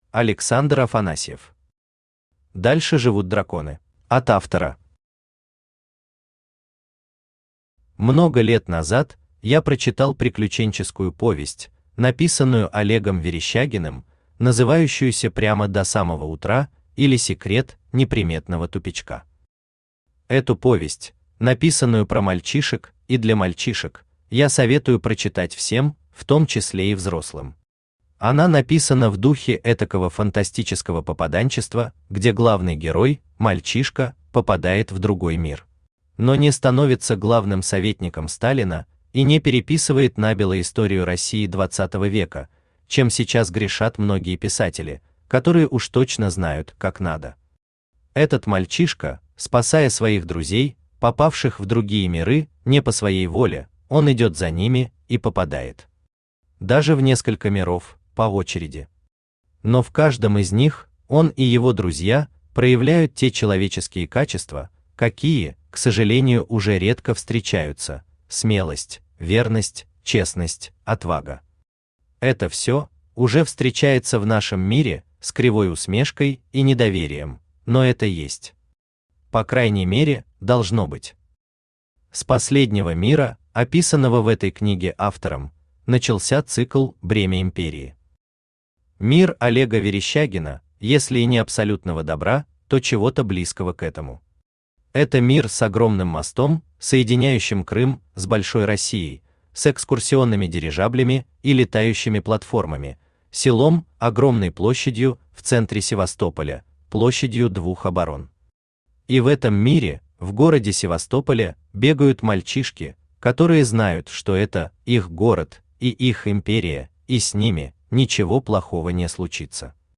Аудиокнига Дальше живут драконы | Библиотека аудиокниг
Aудиокнига Дальше живут драконы Автор Александр Афанасьев Читает аудиокнигу Авточтец ЛитРес.